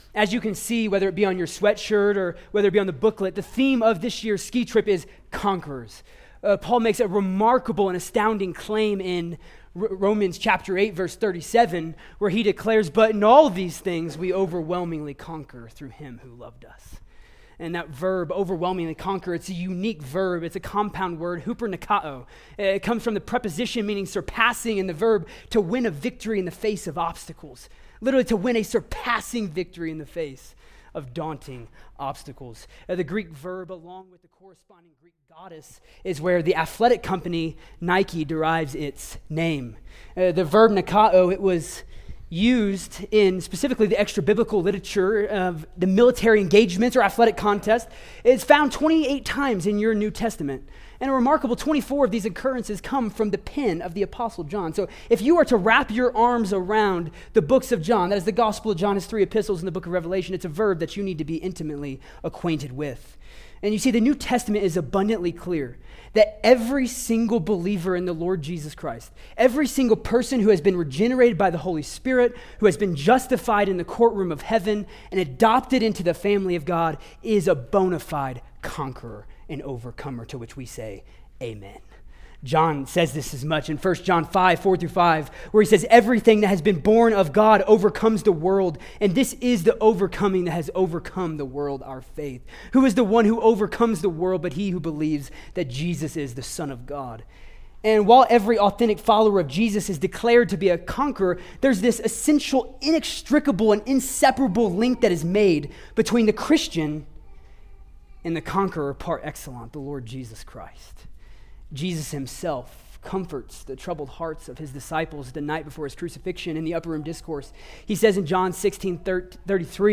College/Roots Roots Winter Retreat - 2025 Audio Series List Next ▶ Current 1.